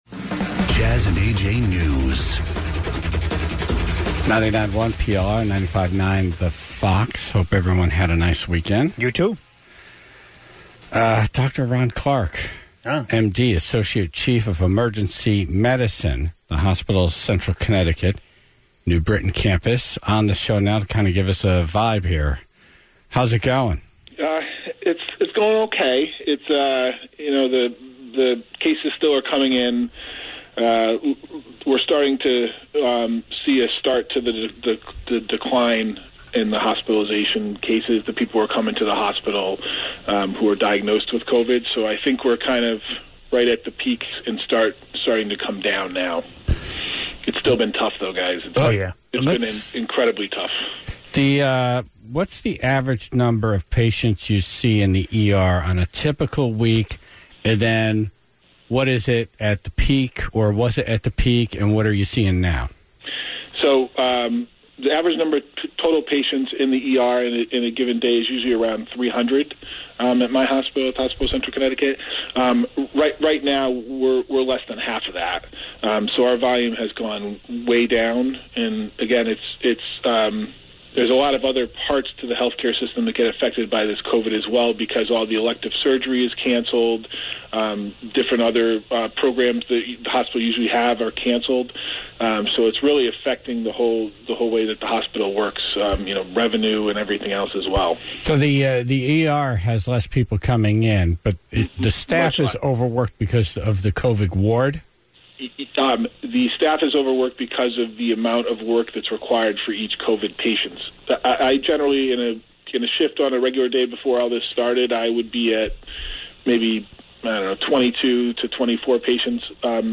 (0:00) DEEP Commissioner Katie Dykes on the phone to talk about the problems with the state parks, since so many people are out there not properly following social distancing guidelines.